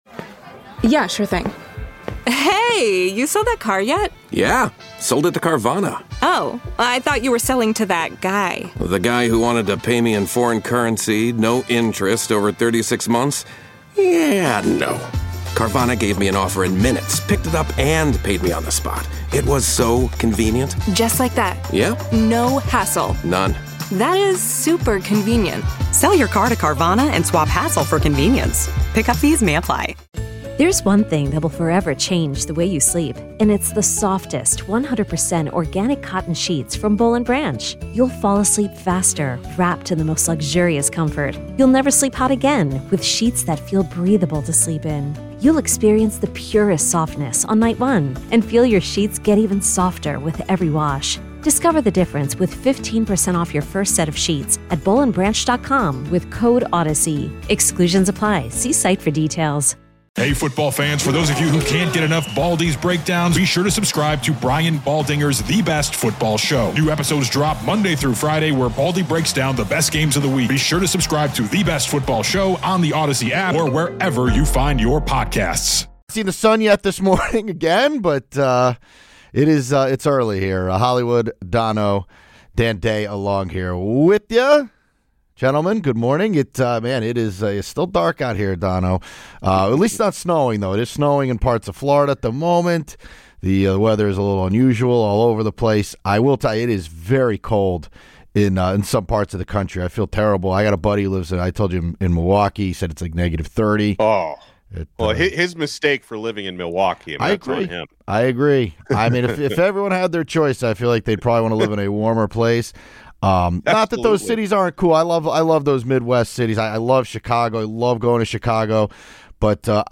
1 Bryson DeChambeau Talks His YouTube Channel, His Diet and The Future of LIV Golf 28:17 Play Pause 11h ago 28:17 Play Pause Später Spielen Später Spielen Listen Gefällt mir Geliked 28:17 Today’s podcast features a sit-down interview with two-time golf major champion and LIV Golf superstar, Bryson DeChambeau. I talk with Bryson about his YouTube channel, his diet, his new deal with Reebok, a new mixed-use development he’s building in his hometown, the future of LIV Golf, and more.